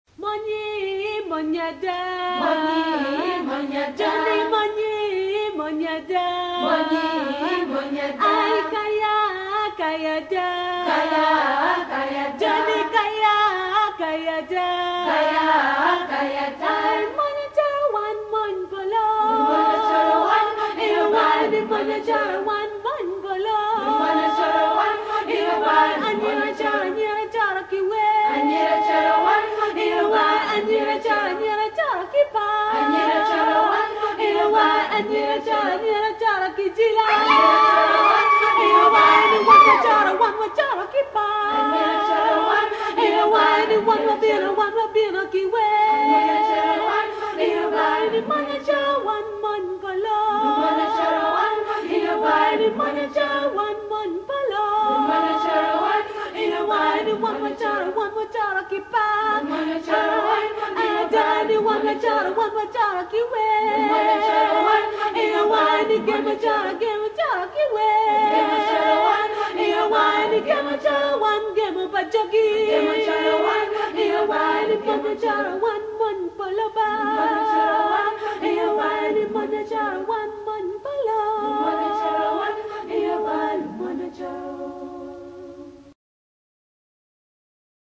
In their Hour of Praise they sang 27 items.